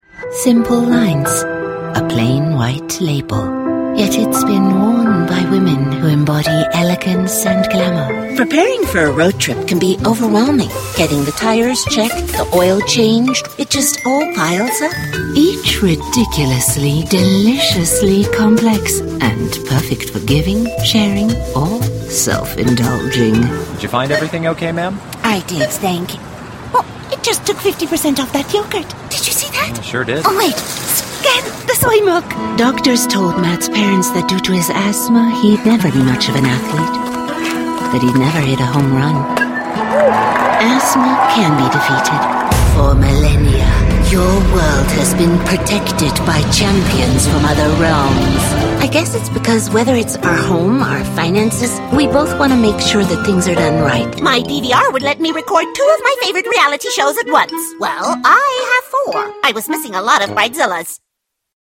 English voice over service